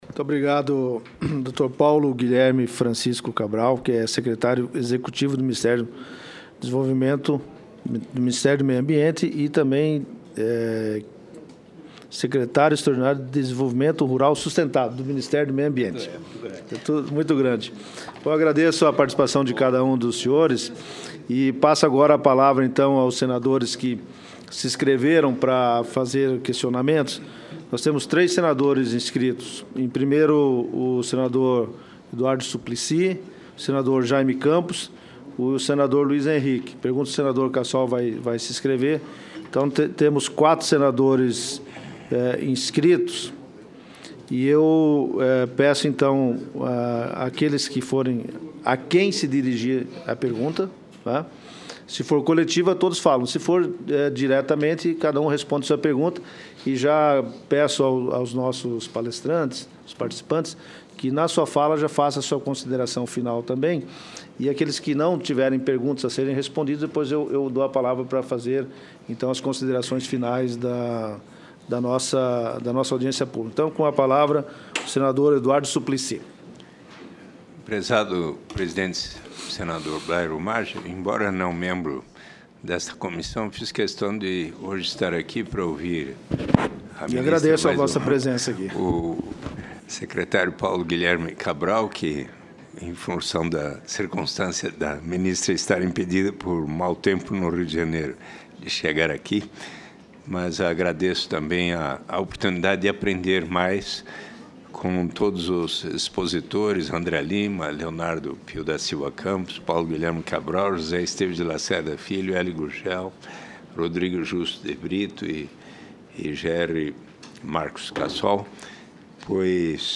Confira a íntegra dos principais debates da Comissão de Meio Ambiente, Defesa do Consumidor e Fiscalização e Controle do Senado